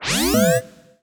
sci-fi_power_on_rise_beep_01.wav